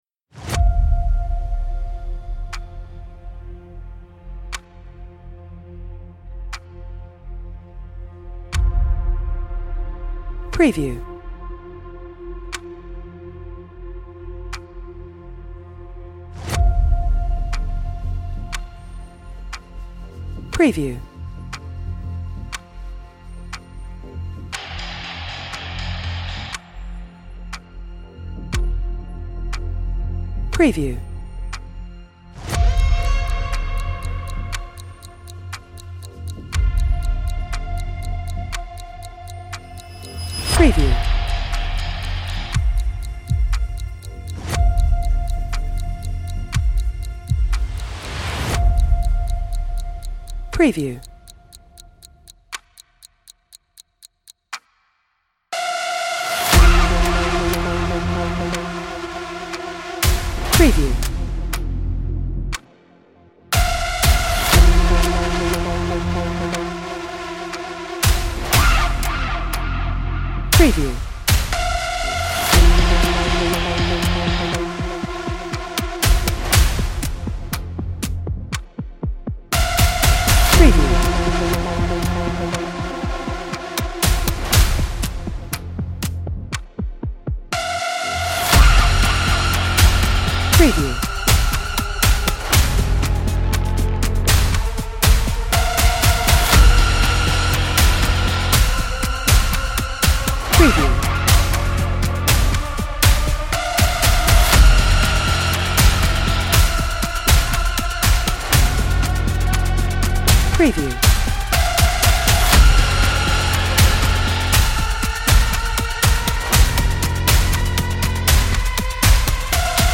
Ticking-Clock Music
Suspenseful Ticking Clock Music for Maximum Impact